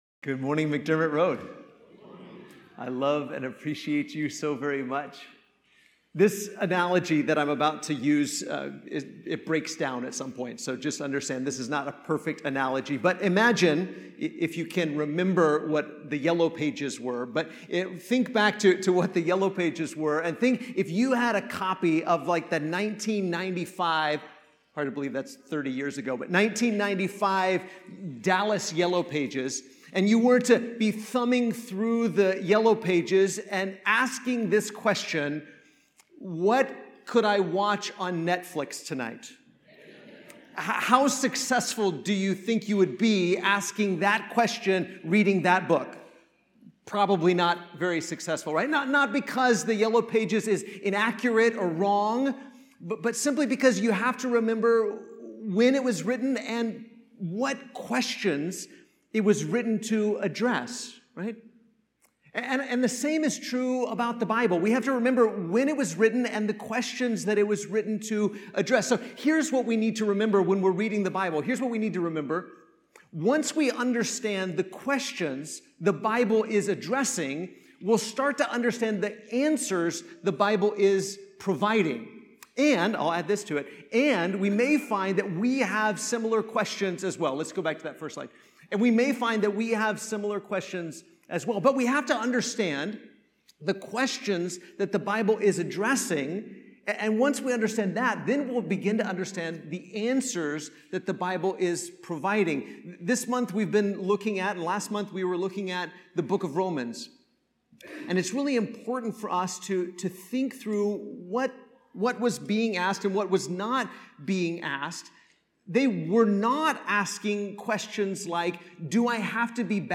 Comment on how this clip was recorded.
Slides that accompany the Sunday morning sermon